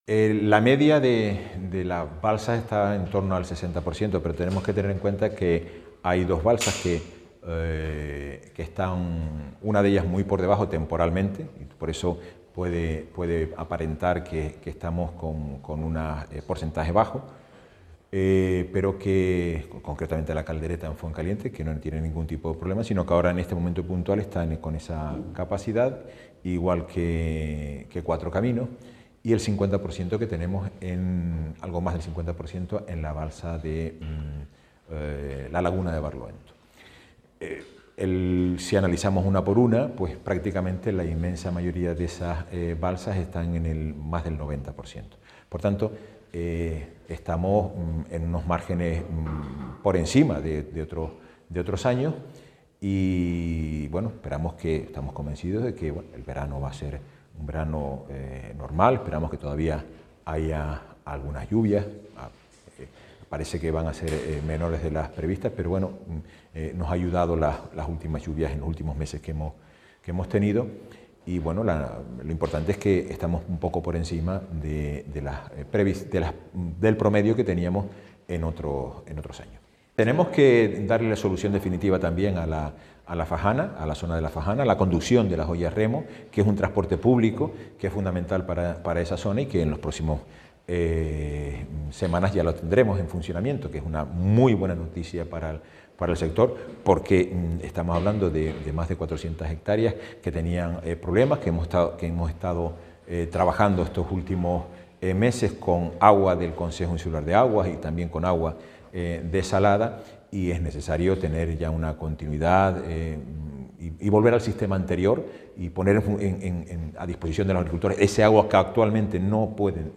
El presidente del Cabildo de La Palma, Mariano Zapata, junto al consejero de Aguas, Carlos Cabrera, han comparecido hoy en rueda de prensa, para presentar la compaña de concienciación en materia hidraúlia ‘Compromiso con el Agua’, coincidiendo con el Día Mundial del Agua.
Declaraciones_audio_Carlos_Cabrera_balsas_de_agua.mp3